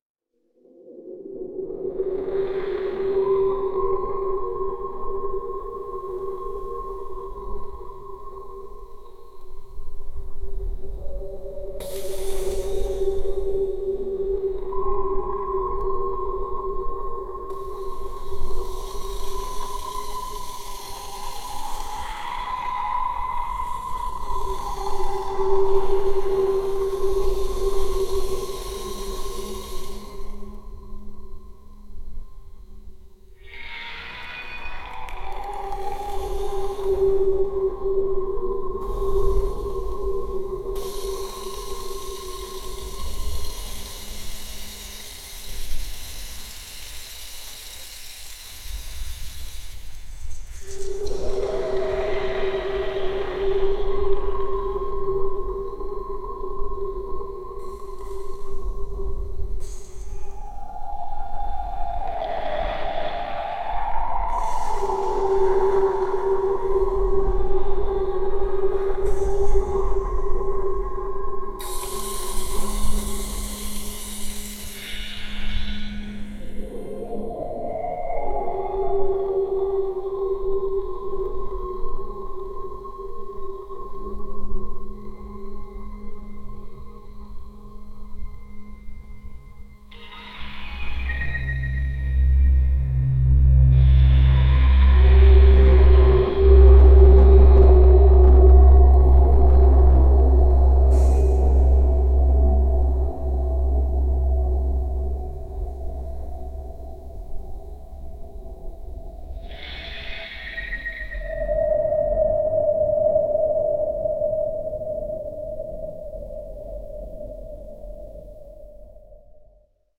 Ambient, creepy, background music, whispering sounds, winds, slow tempo, eerie, abyss
abyss-background.mp3